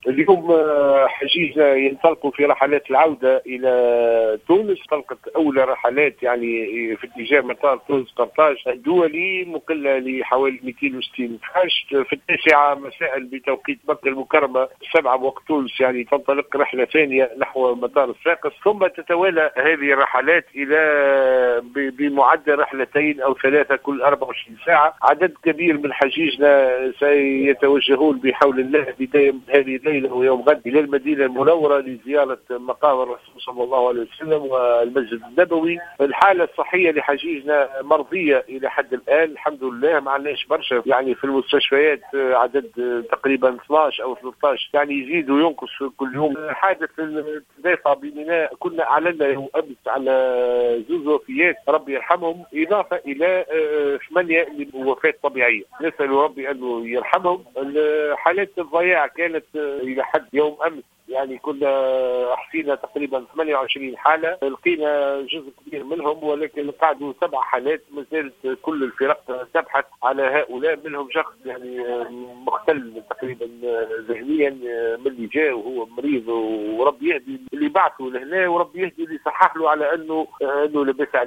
dans une intervention sur les ondes de Jawhara FM